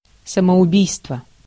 Ääntäminen
Synonyymit суицид Ääntäminen Tuntematon aksentti: IPA: /səməʊˈbʲijstvɘ/ Haettu sana löytyi näillä lähdekielillä: venäjä Käännös Konteksti Substantiivit 1. suicide kuvaannollinen Translitterointi: samoubijstvo.